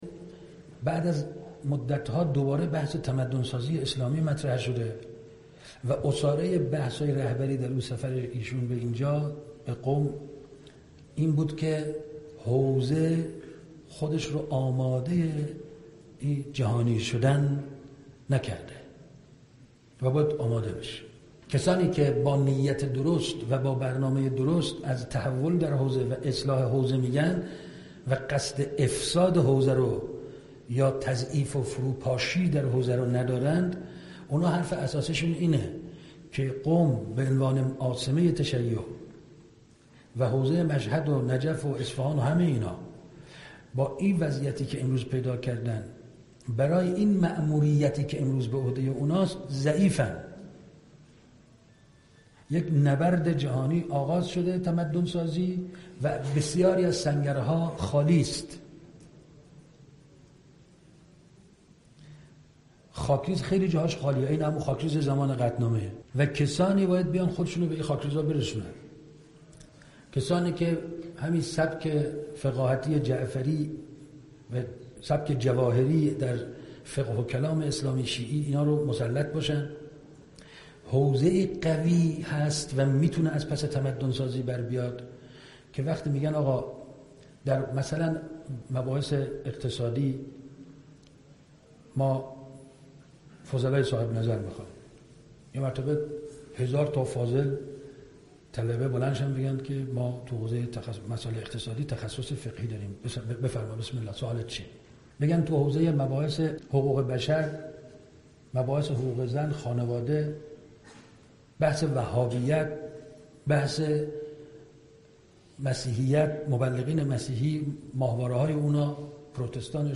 در پایان مخاطبین محترم را به تأمل در قطعه ای از سخنان استاد حسن رحیم پورازغدی دعوت می نماییم: